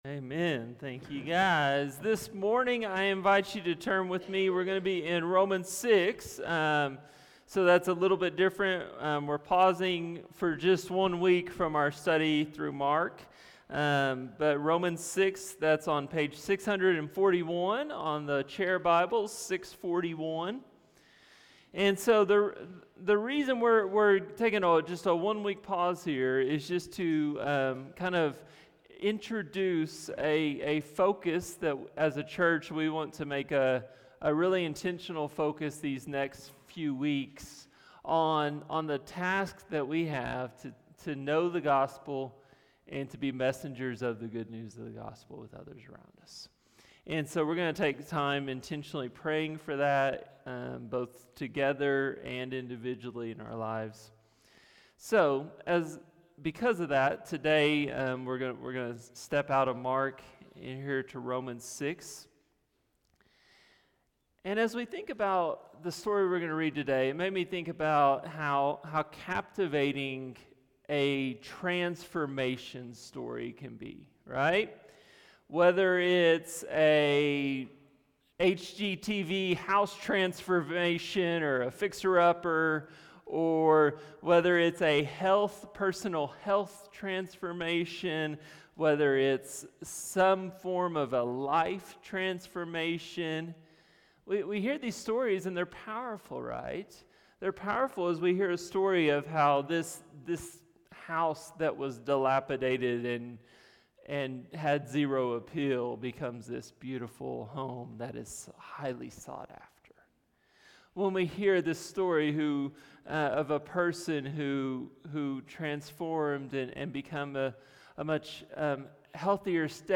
From Series: "Stand-Alone Sermons"